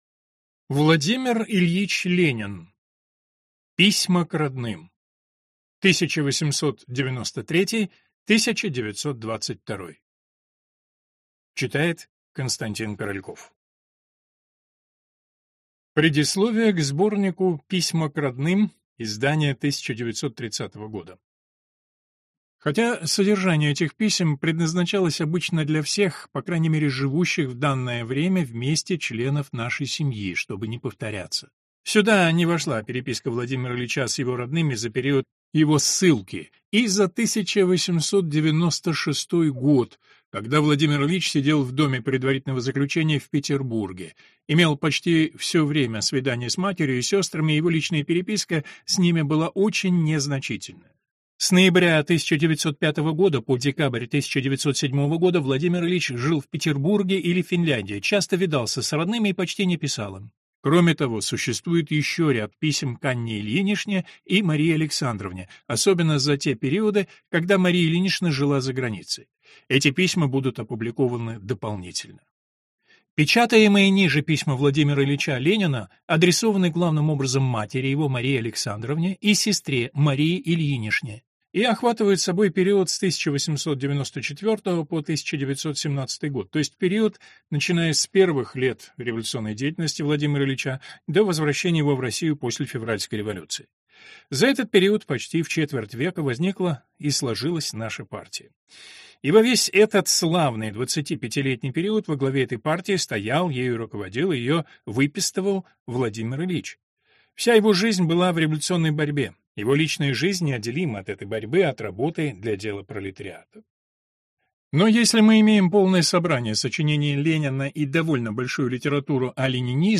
Аудиокнига Полное собрание сочинений. Том 55. Письма к родным 1893 – 1922 | Библиотека аудиокниг